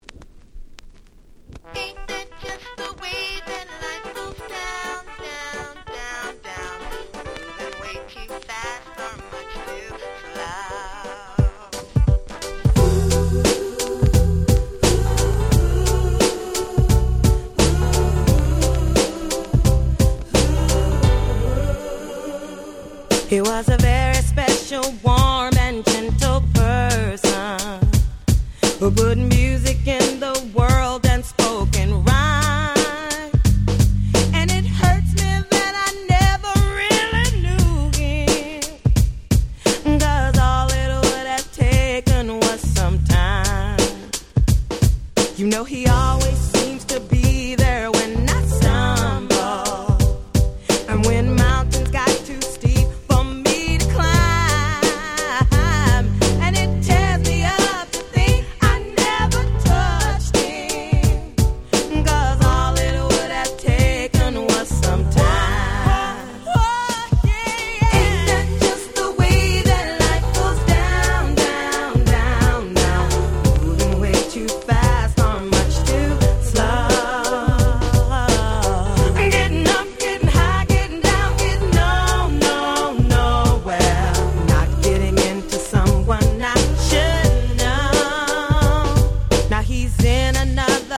90's 00's R&B キャッチー系 Dance Pop ダンスポップ